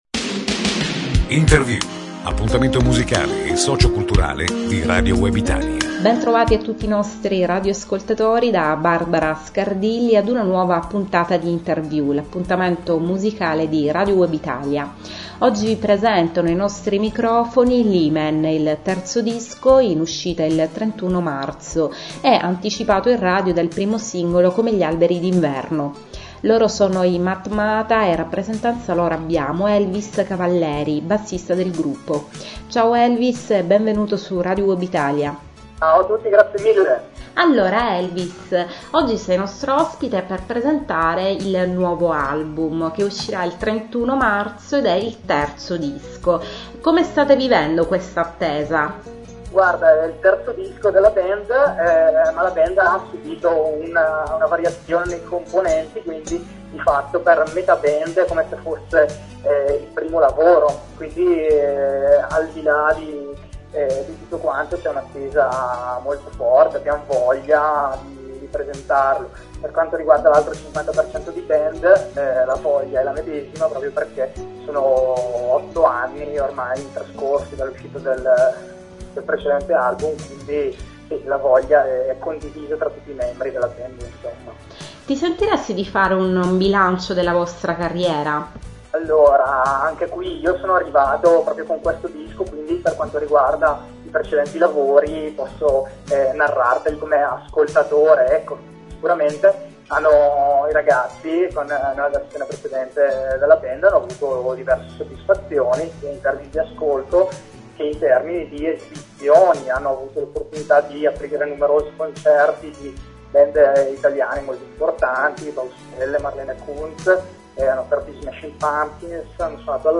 matmata-intervista.mp3